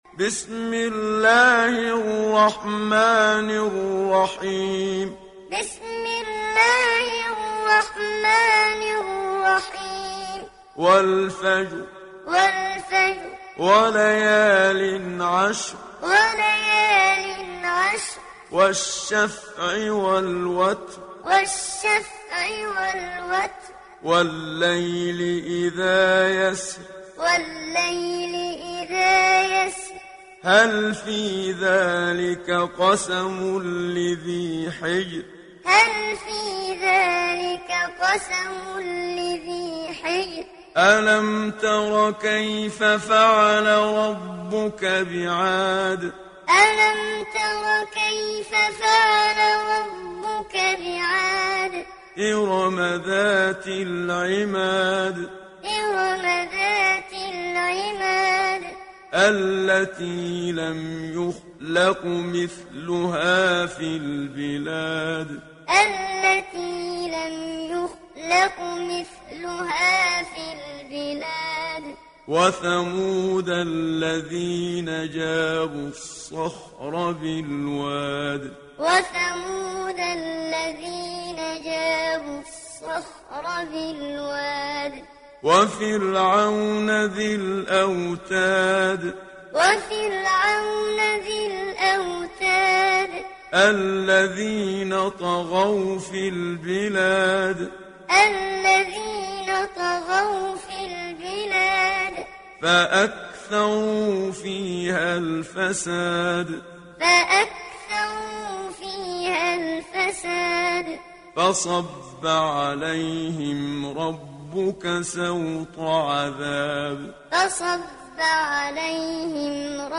Hafs an Asim
Muallim